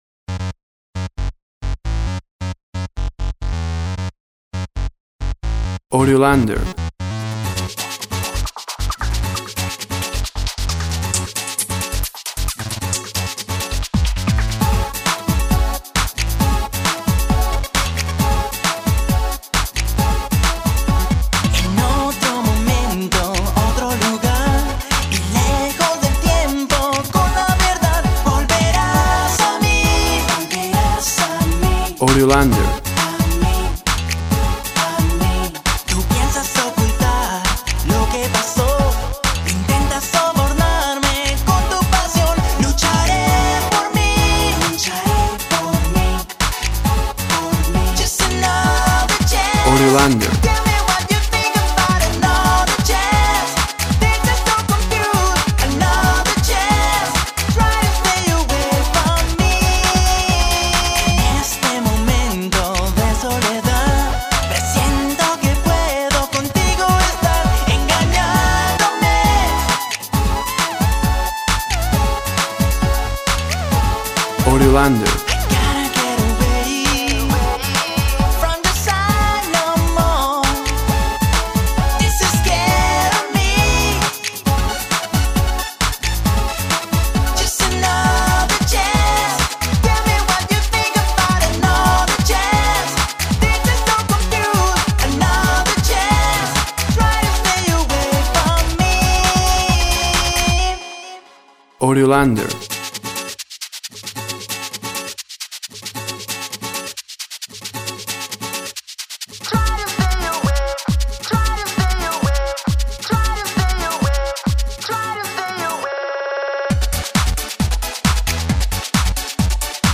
Electro pop, energy, power, party, club, dance.
Tempo (BPM) 100